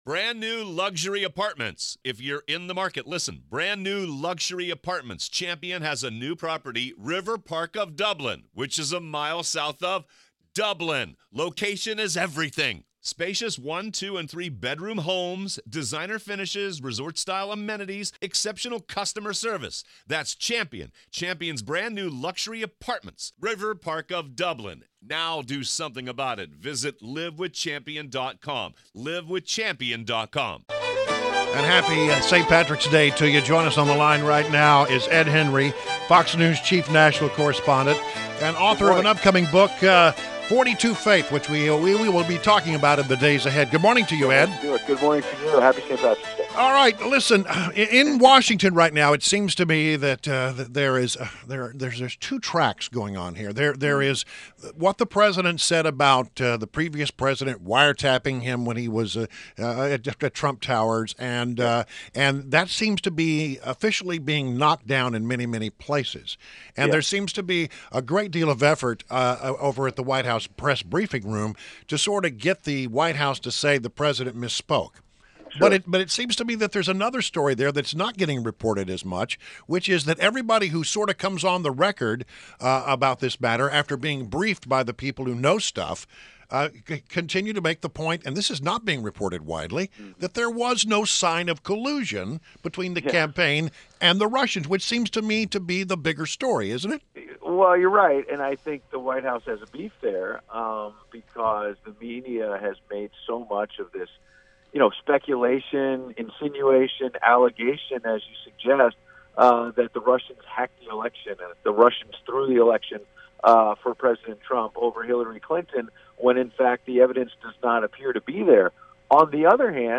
WMAL Interview - ED HENRY - 03.17.17
INTERVIEW – ED HENRY – Fox News Chief National Correspondent; and author of upcoming book “42 Faith” – discussed the latest political news of the day: Health care fighting, Wiretapping and Budget battle.